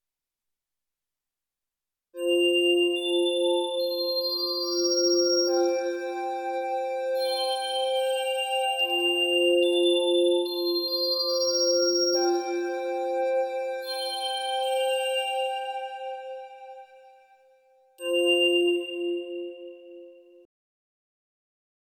72 BPM
F major pentatonic ascending glass bells + warm fifth pad